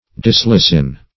Meaning of dyslysin. dyslysin synonyms, pronunciation, spelling and more from Free Dictionary.
Search Result for " dyslysin" : The Collaborative International Dictionary of English v.0.48: Dyslysin \Dys"ly*sin\, n. [Gr. dys- ill, hard + ? a loosing.]